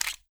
glock20_magout.wav